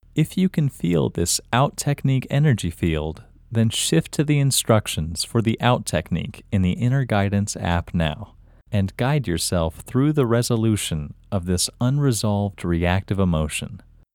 LOCATE OUT English Male 30